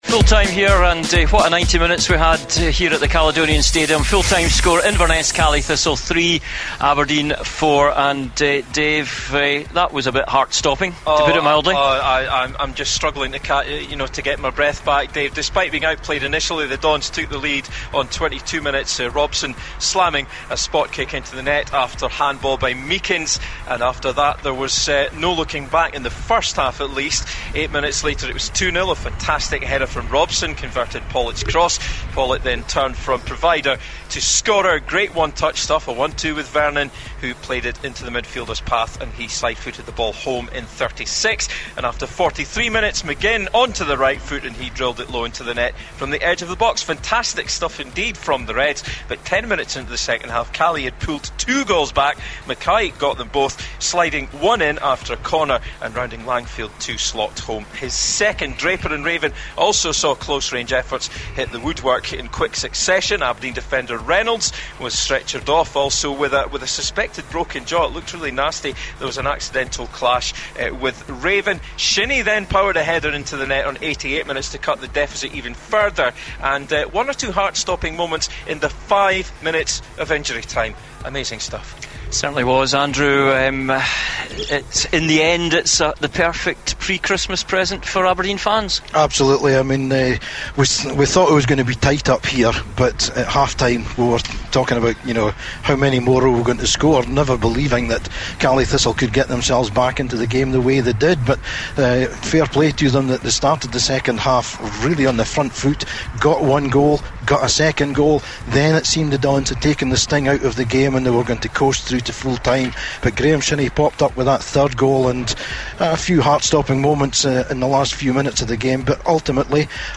at the Inverness Caledonian Stadium. It finished Inverness 3 Aberdeen 4. The Dons claim the three points and move into joint second place in the SPFL Premiership